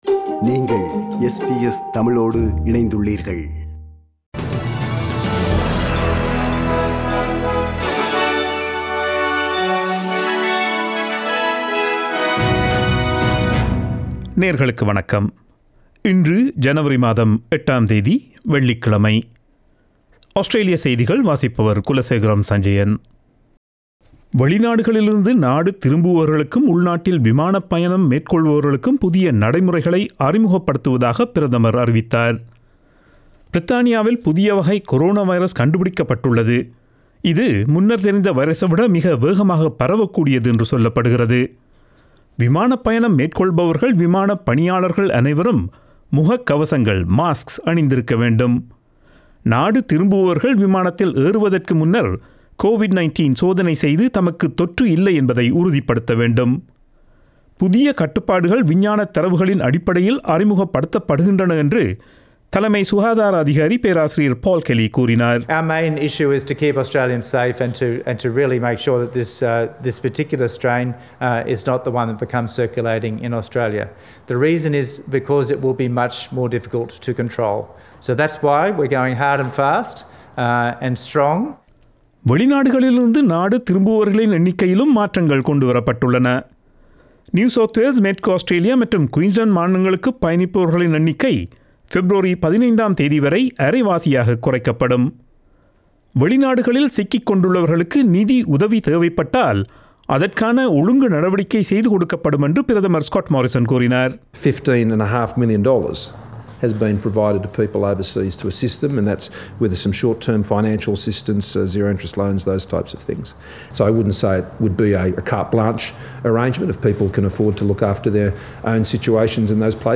Australian news bulletin for Friday 08 January 2021.